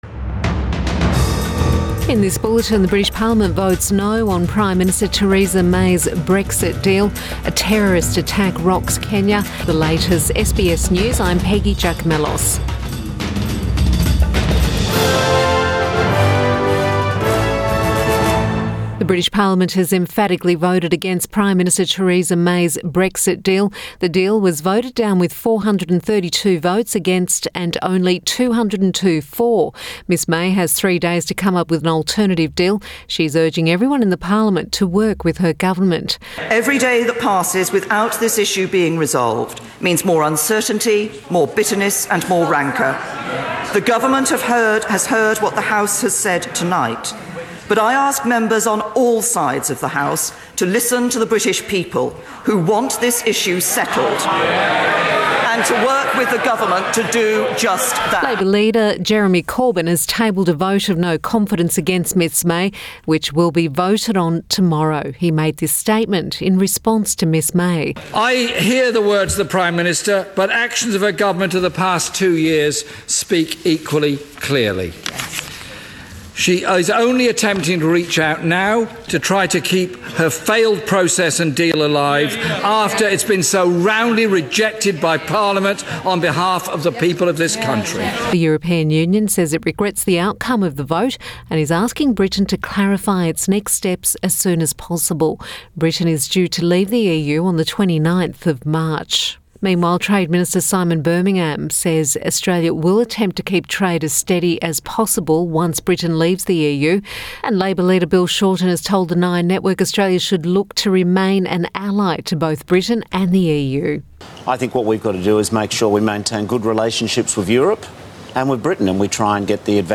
Midday Bulletin Jan 16